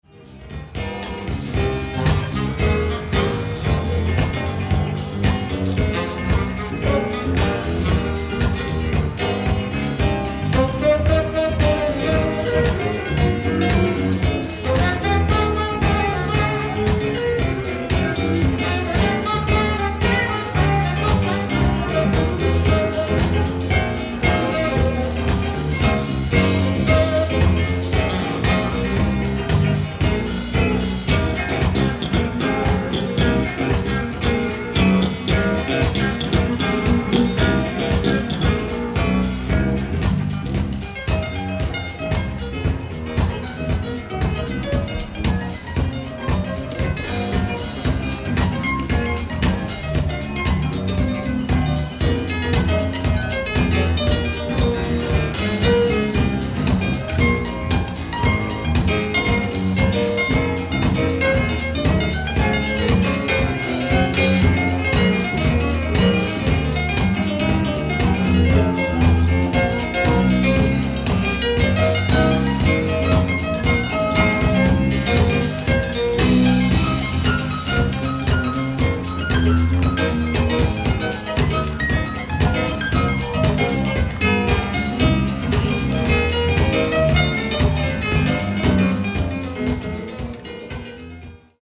ライブ・アット・プレイボーイ・ジャズ・フェスティバル、ハリウッド・ボウル、06/21/1981
※試聴用に実際より音質を落としています。